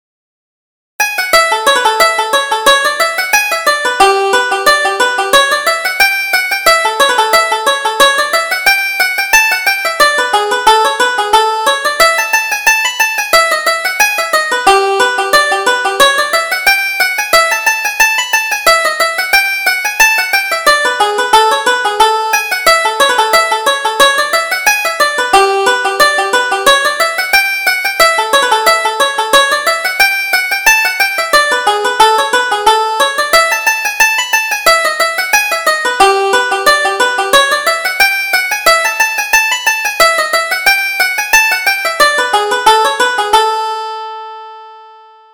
Reel: The Swallow's Tail